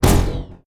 EXPLOSION_Arcade_07_mono.wav